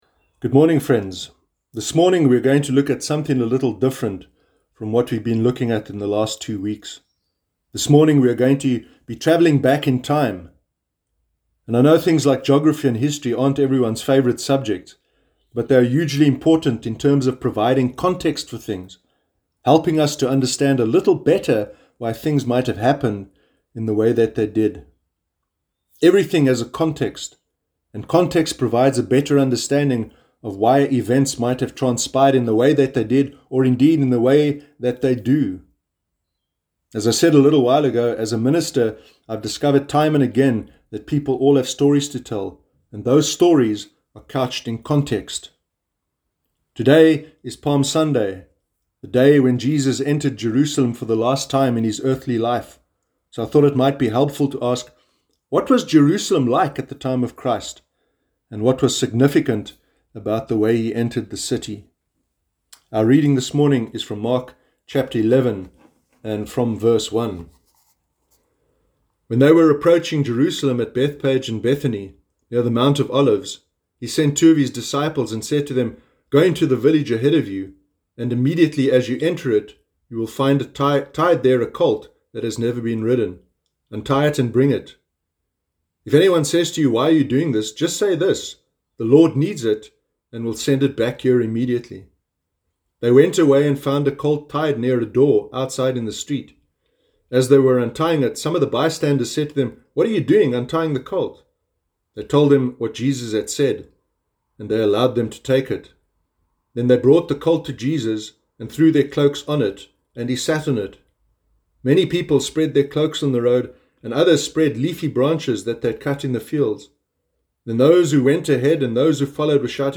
Sermon Palm Sunday 5 April 2020
sermon-sunday-5-april-2020.mp3